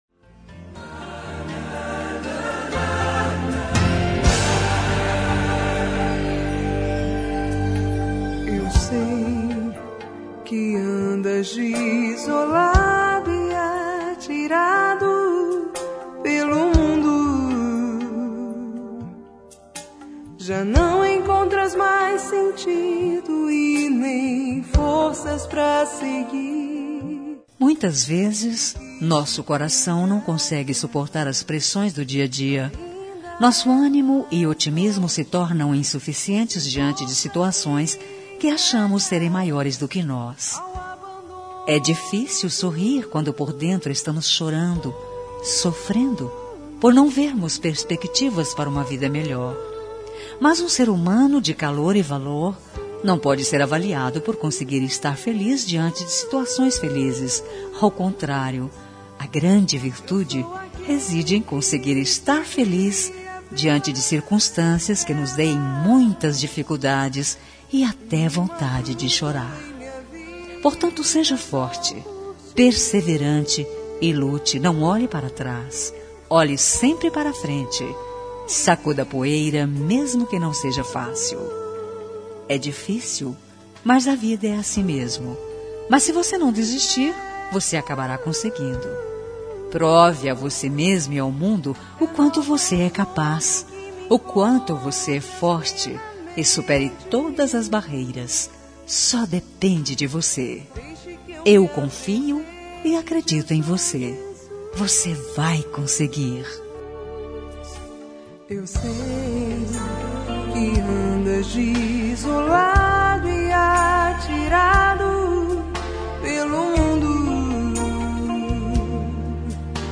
Telemensagem de Otimismo – Voz Feminina – Cód: 178